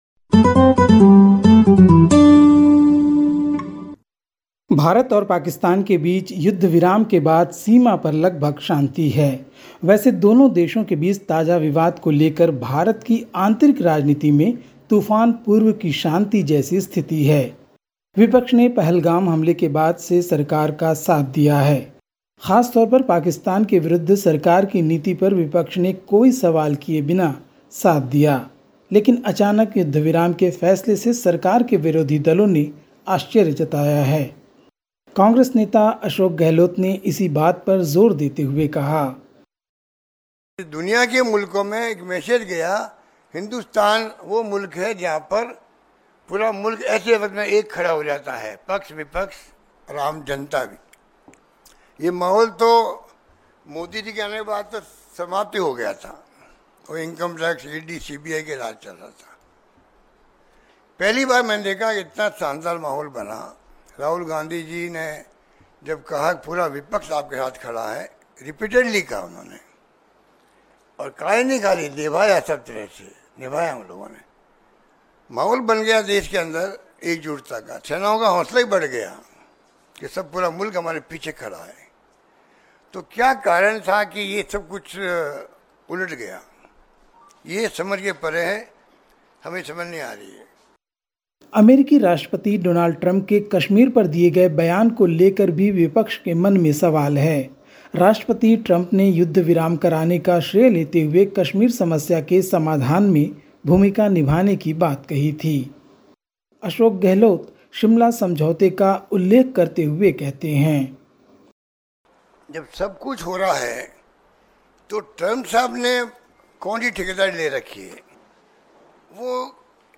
Listen to the latest SBS Hindi news from India. 14/05/2025